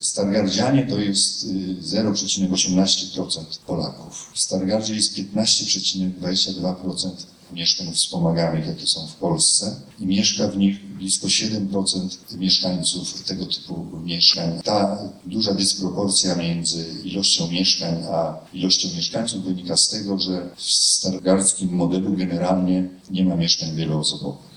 „Mieszkania wspomagane w polityce mieszkaniowej i społecznej samorządów” – konferencja pod takim hasłem odbywa się w Stargardzkim Centrum Kultury.
W Stargardzie Towarzystwo Budownictwa Społecznego przez 20 lat działalności wypracowało pionierskie rozwiązania w zakresie polityki mieszkaniowej – uważa Piotr Mync, zastępca prezydenta miasta.